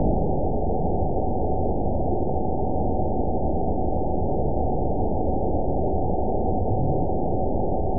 event 921785 date 12/19/24 time 01:37:01 GMT (4 months, 2 weeks ago) score 7.85 location TSS-AB01 detected by nrw target species NRW annotations +NRW Spectrogram: Frequency (kHz) vs. Time (s) audio not available .wav